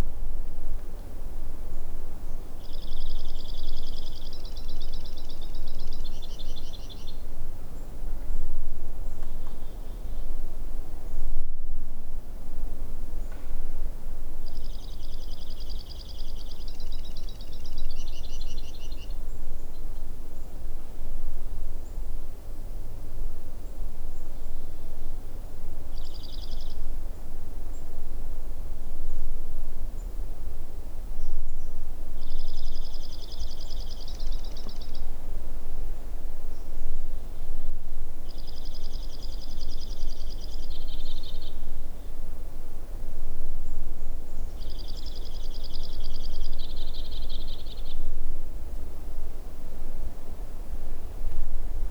White-winged Crossbill
Summerhill (Salt Rd. near Dresser Rd.), 13 August 2008
Short clip of singing male given below